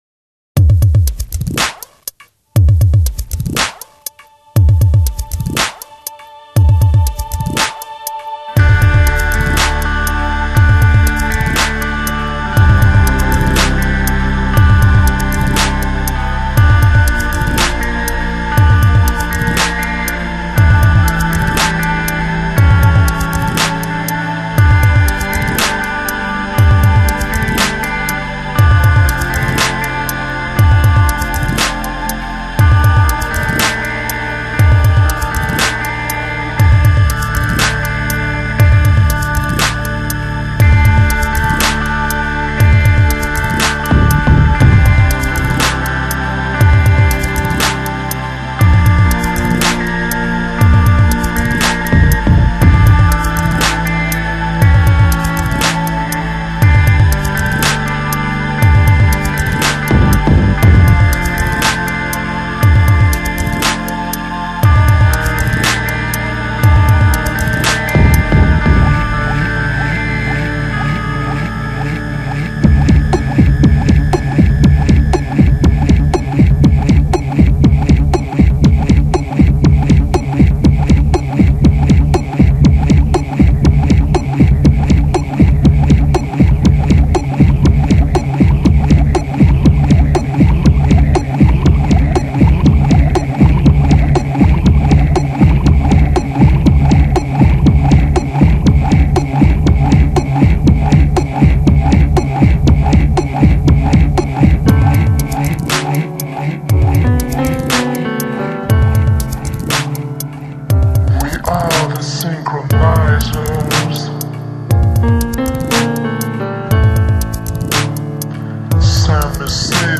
浪漫的怀旧情绪依然存在，光怪陆离的电子采样之声也应有尽有
在震撼的电子鼓点中加进了浪漫的吉他和钢琴演奏，以及奇怪的采样声响，再配上半说半唱的呢喃，显得有点阴暗而动听